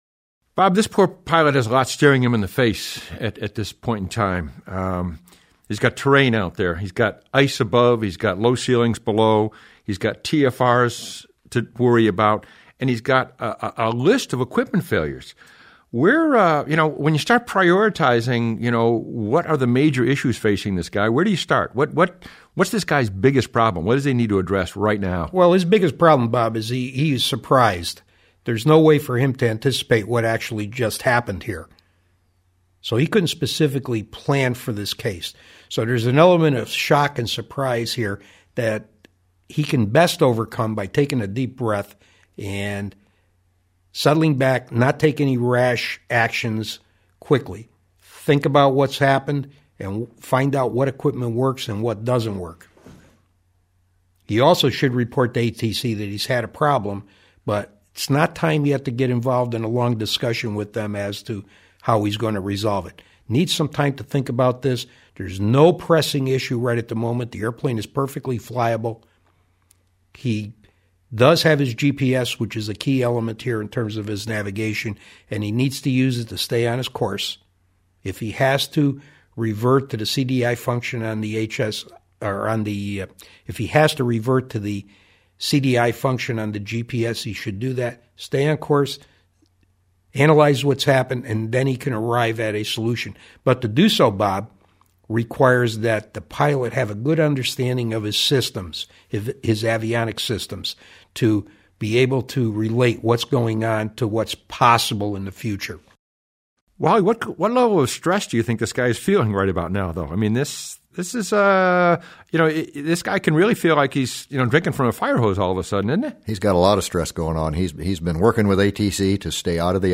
57-Roundtable.mp3